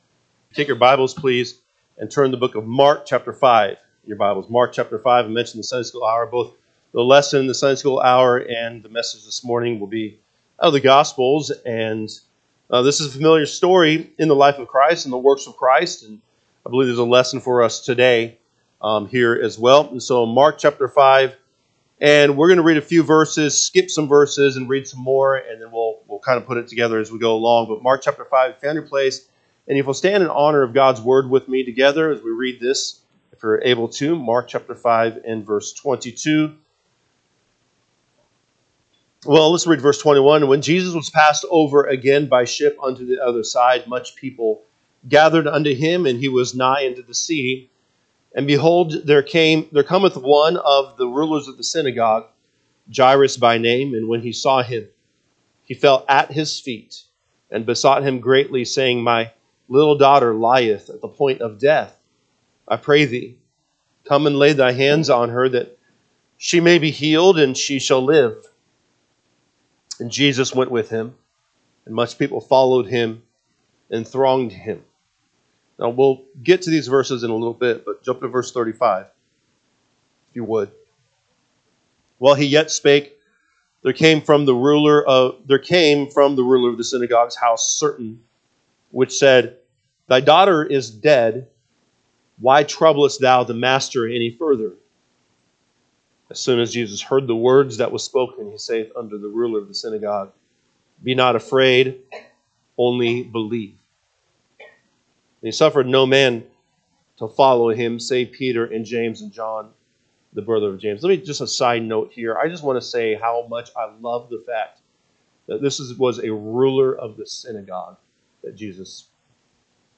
March 9, 2025 am Service Mark 5:21-24, 35-43 (KJB) 21 And when Jesus was passed over again by ship unto the other side, much people gathered unto him: and he was nigh unto the sea. 22 And…
Sunday AM Message